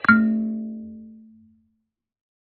kalimba2_wood-A2-pp.wav